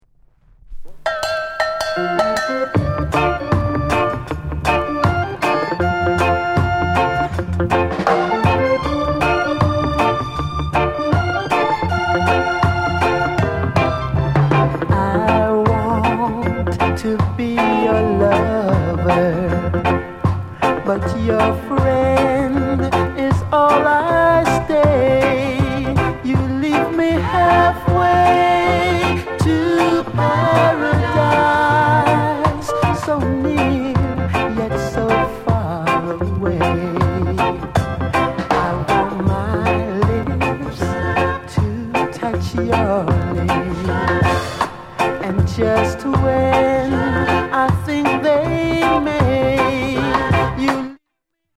NICE VOCAL ROCKSTEADY